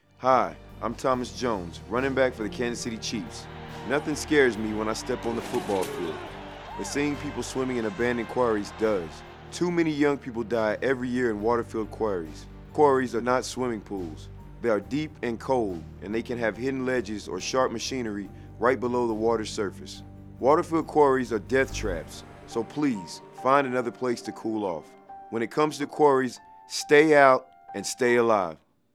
Thomas Jones of the Kansas City Chiefs prepared PSAs for the US Department of Labor